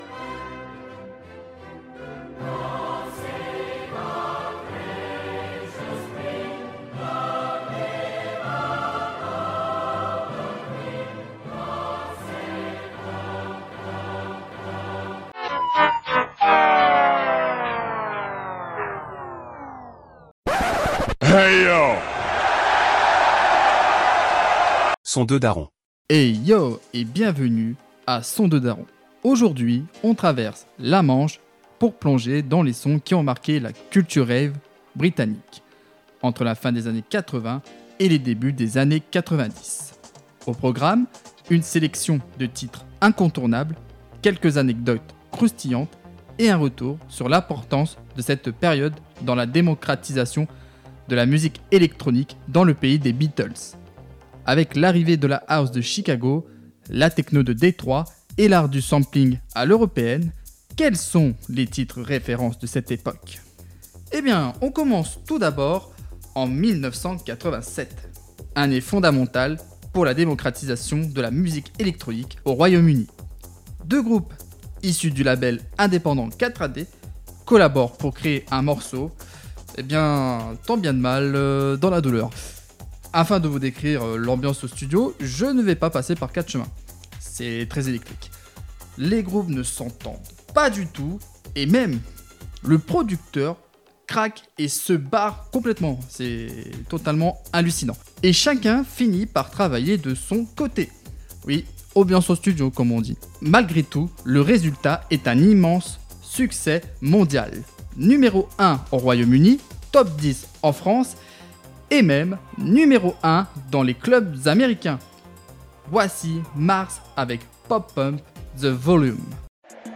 Anecdotes, repères historiques et sélection musicale se mêlent pour mieux comprendre comment la house et la techno ont conquis les pistes de danse et posé les bases de la scène électronique moderne.